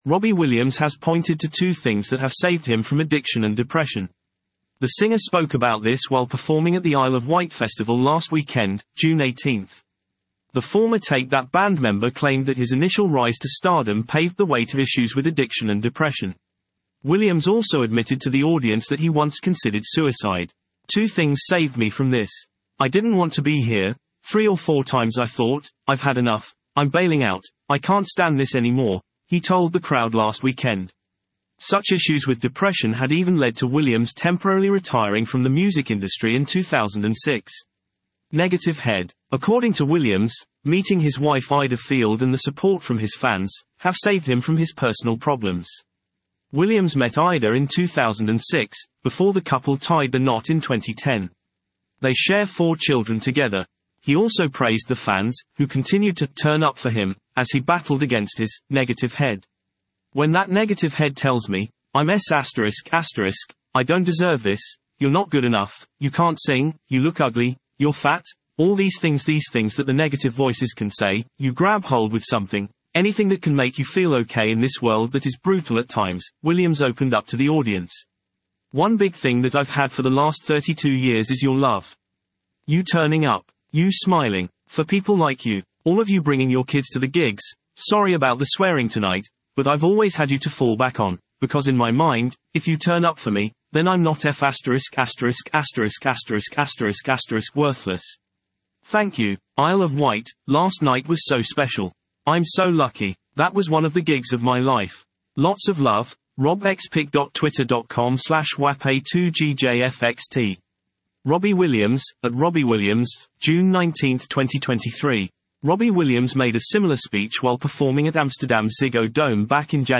The singer spoke about this while performing at the Isle of Wight Festival last weekend (June 18).